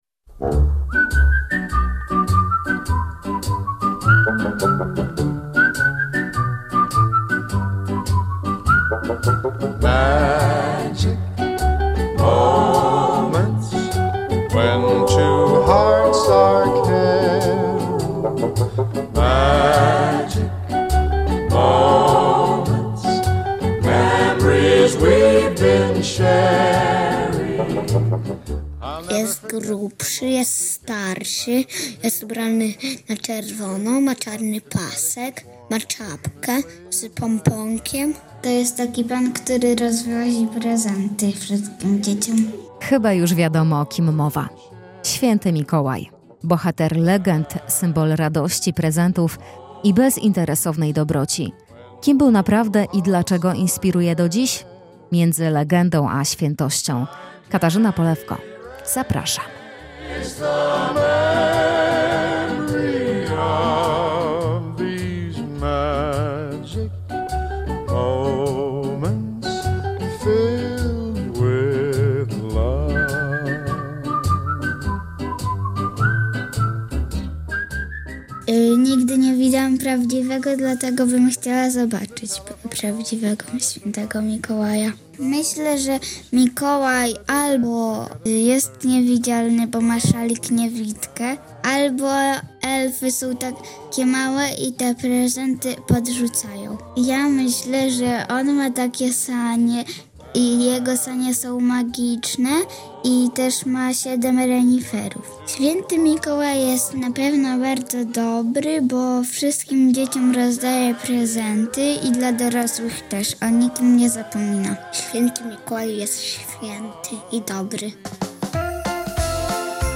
Świąteczny reportaż RN: Św. Mikołaj – między legendą, a świętością - Radio Nadzieja